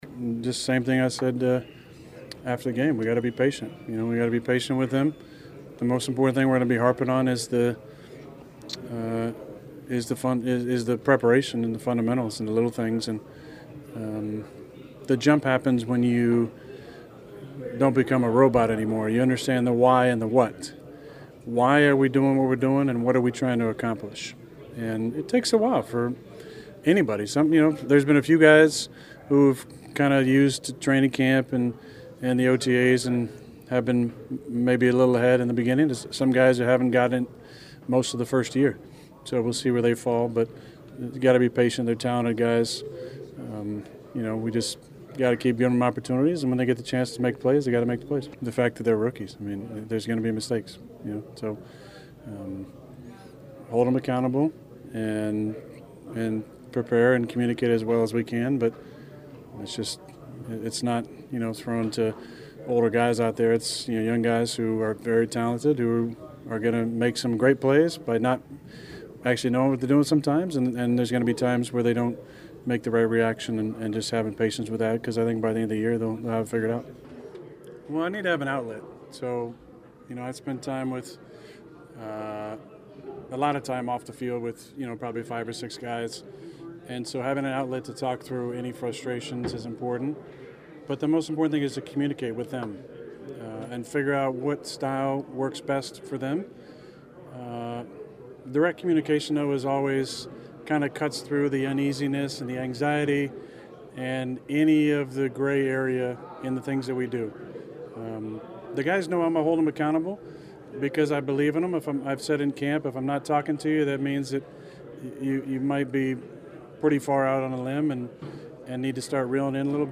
It was the rest of the wide receivers, especially the rookies that kept Aaron Rodgers busy during his once a week post-practice media session.   He covered a lot of ground about the opening day performances of Christian Watson and Romeo Doubs against the Vikings, discussing how he senses the pro game isn’t too big for the youngsters,  acknowledging they’ll make mistakes, managing his frustrations if any and building up their confidence.